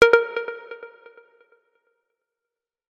hyperalert.mp3